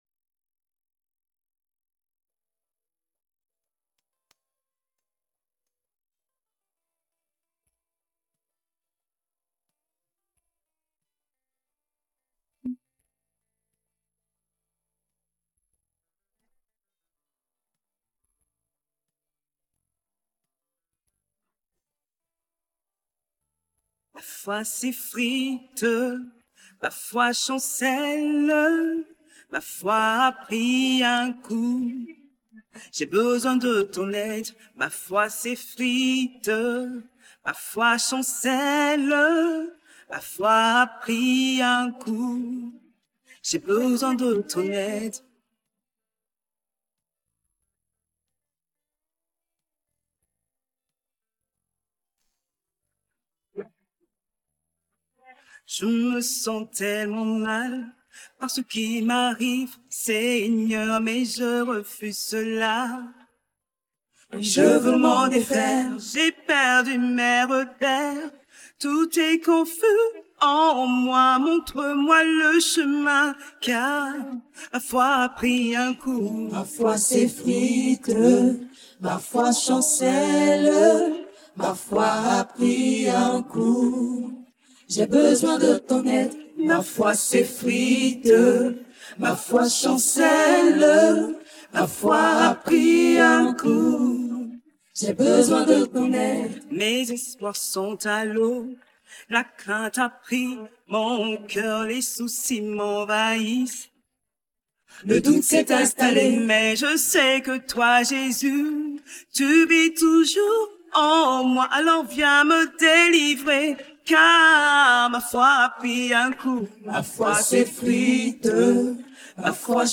Cantique de prière — quand la foi vacille, Dieu reste fidèle